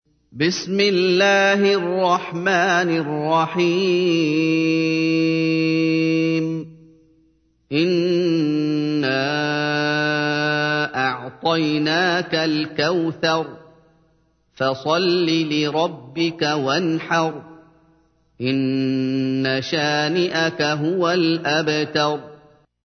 تحميل : 108. سورة الكوثر / القارئ محمد أيوب / القرآن الكريم / موقع يا حسين